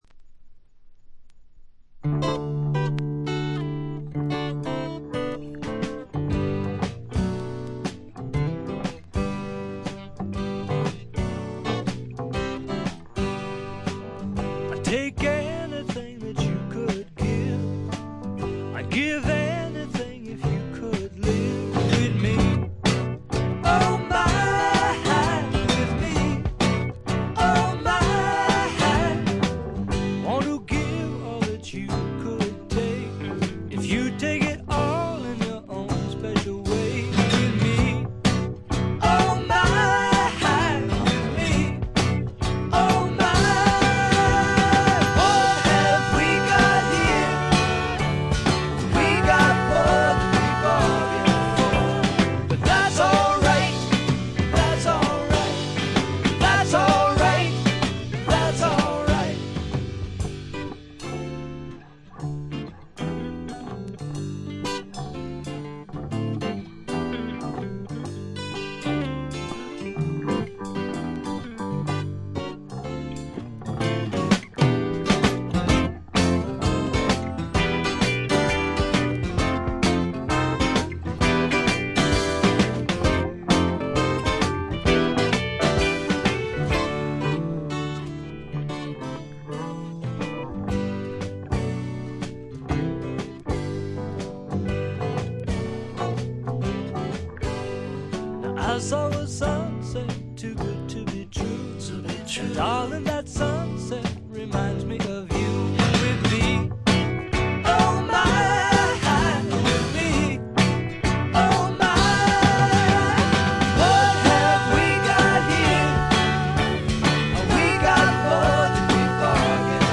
細かなチリプチや散発的なプツ音は少し出ますが良好に鑑賞できます。
内容はいかにもボストンらしく、フォーク、ドリーミー・ポップ、グッタイム・ミュージック等を下地にした実にごきげんなもの。
試聴曲は現品からの取り込み音源です。